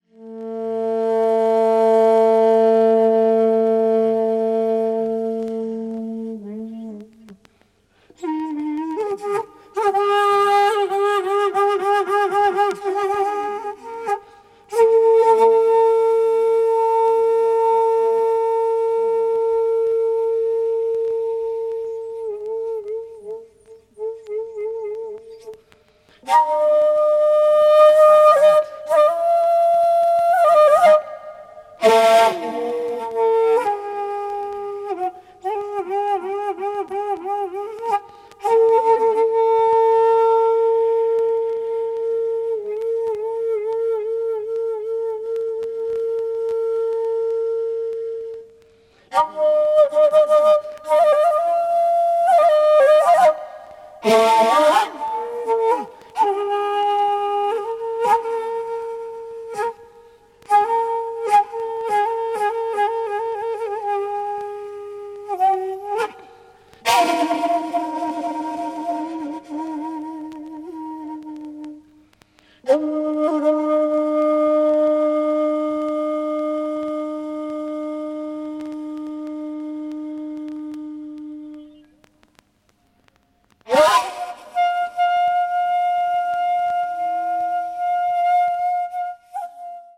media : EX-/EX-(some light surface noises.)